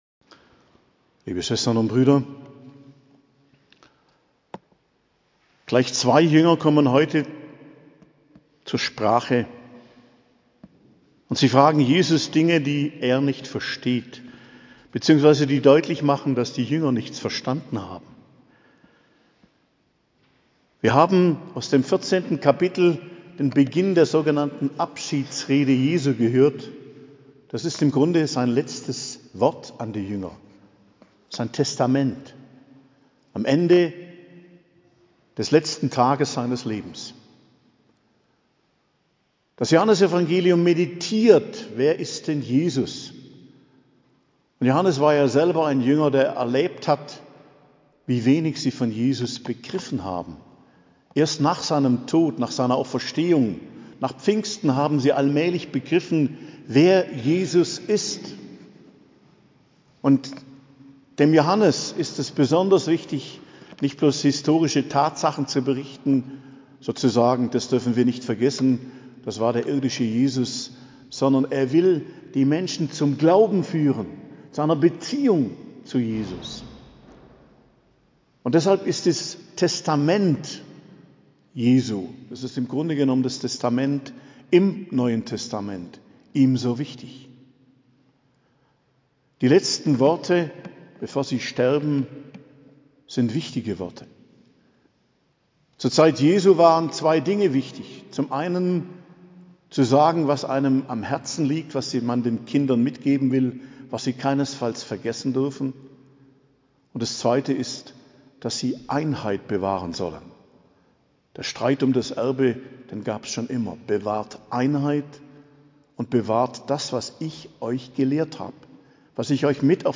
Predigt zum 5. Sonntag der Osterzeit, 7.05.2023